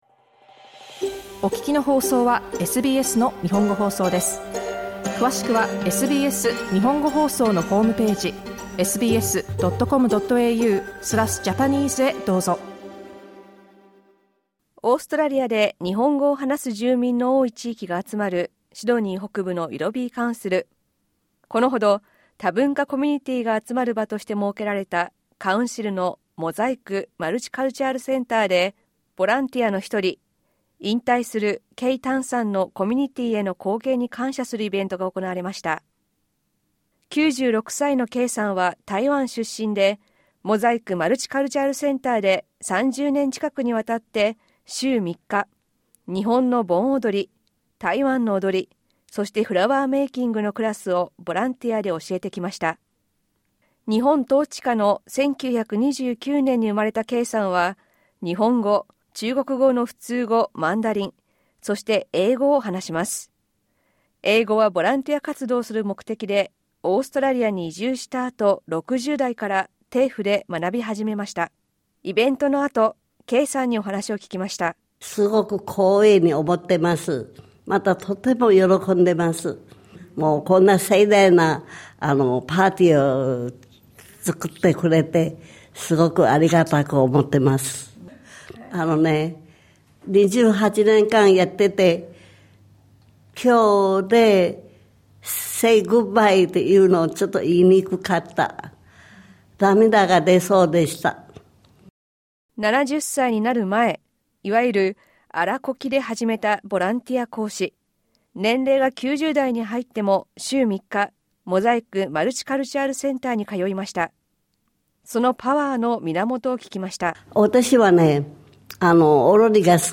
会場でのインタビューなどをまとめました。